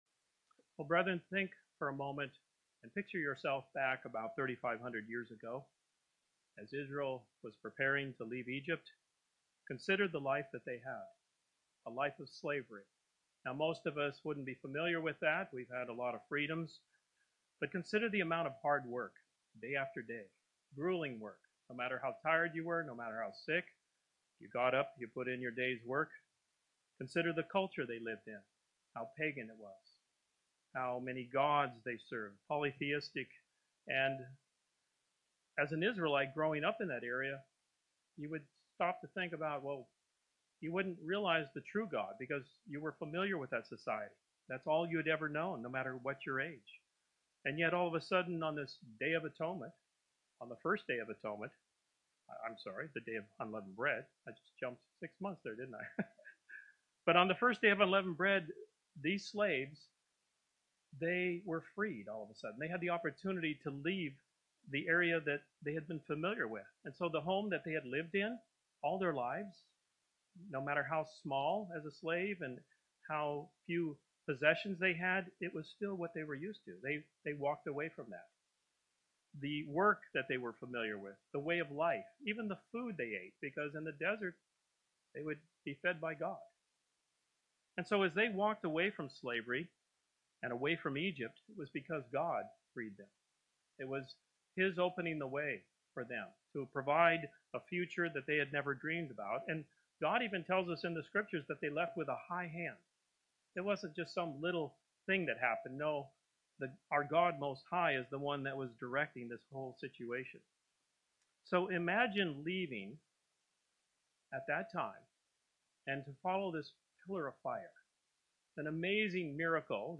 Sermons
Given in St. Petersburg, FL Tampa, FL